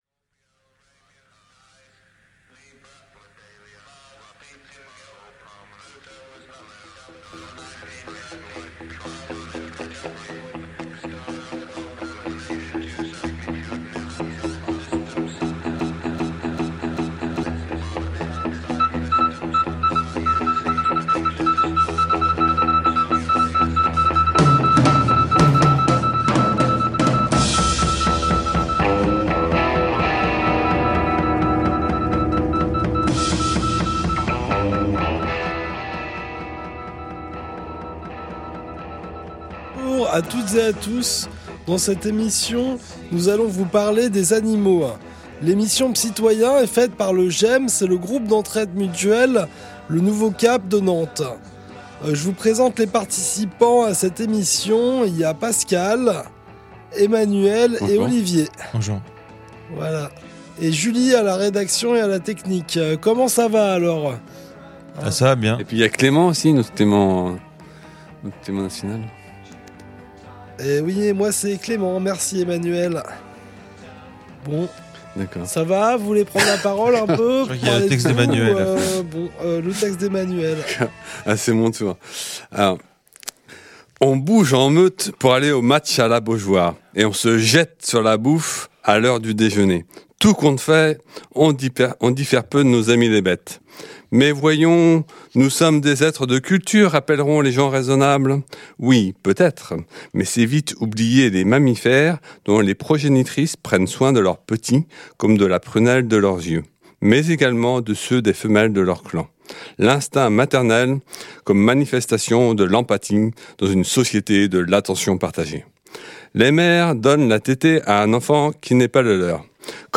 Psytoyens est une émission réalisée par l’atelier radio du GEM de Nantes.
Dans cette émission, il est question d’intelligence animale, le rapport de l’homme avec l’animal, de maltraitance … Des textes, l’interview d’une comportementaliste animale et de l’association Urgence maltraitance animale 44, une création sonore, des chroniques et de la musique !